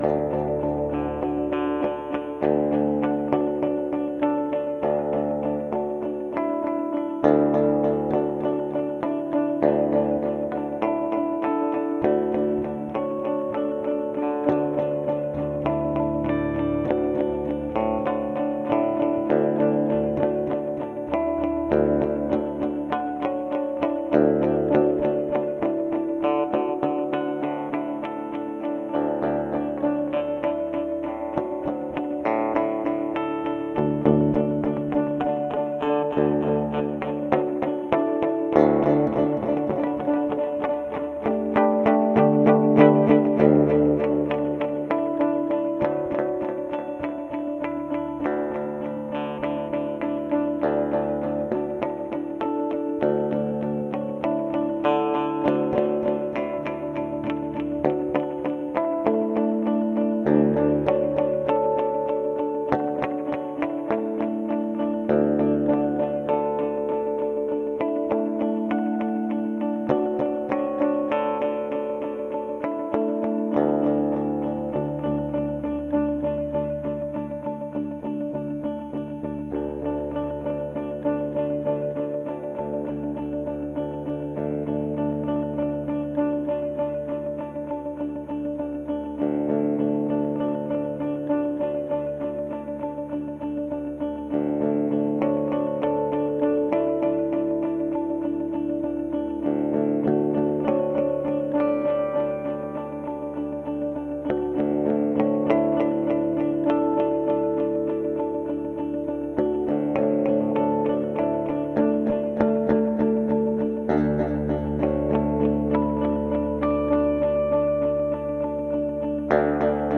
These are a single phrase split in two. I like the harmonics in the 2nd one